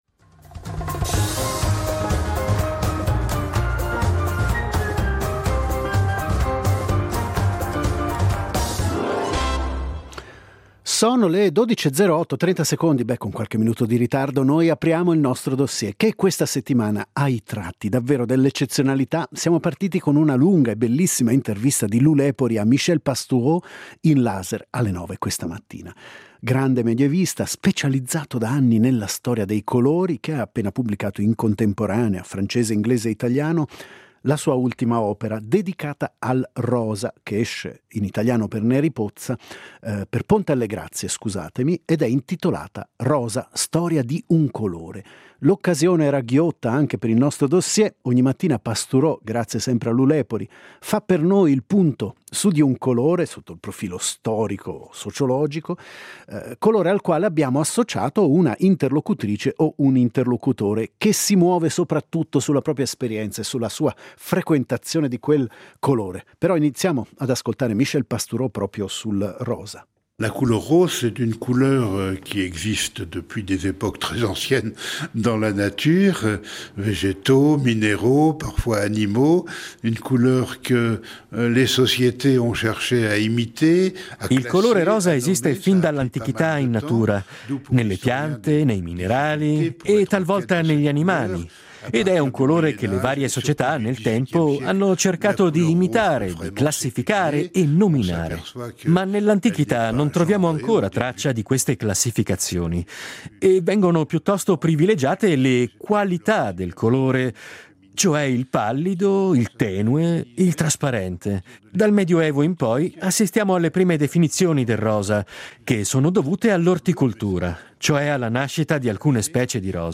Il Dossier questa settimana prende le mosse da una lunga intervista a Michel Pastoureau , autore del saggio “ Rosa. Storia di un colore .”